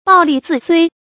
注音：ㄅㄠˋ ㄌㄧˋ ㄗㄧˋ ㄙㄨㄟ
暴戾恣睢的讀法